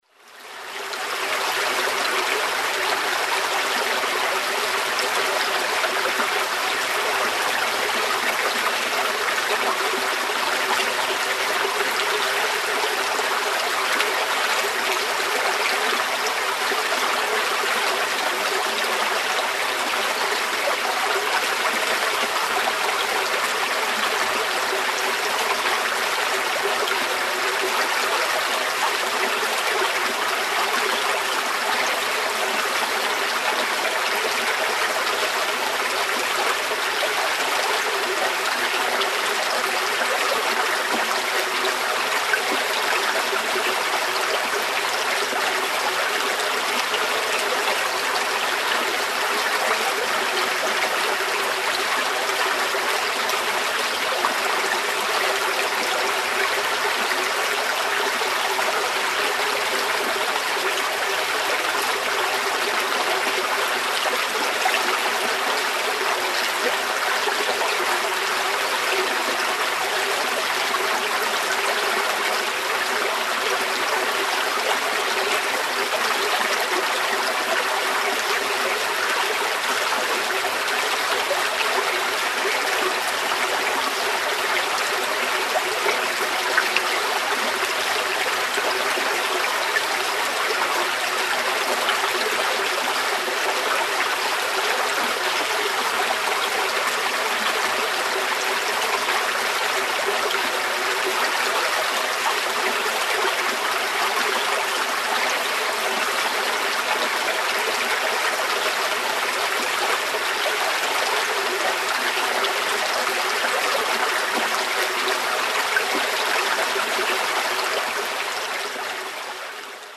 Шум воды: лесной ручей